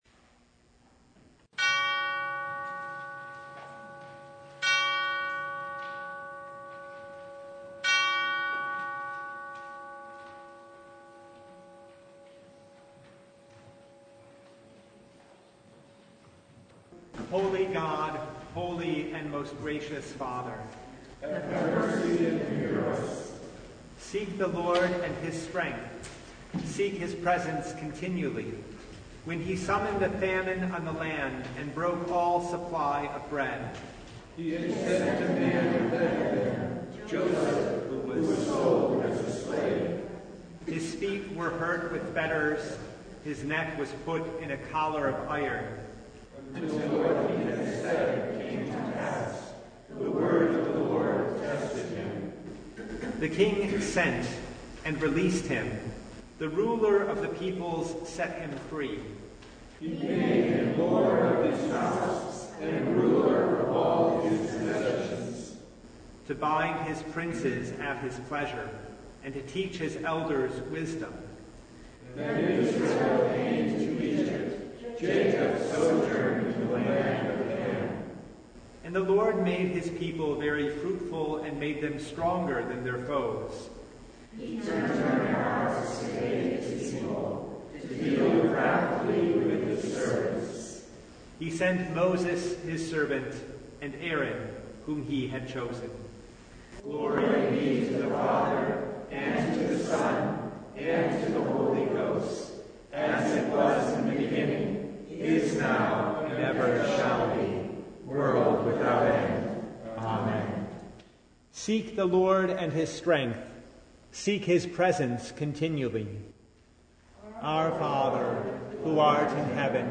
Advent Noon (December 18, 2024)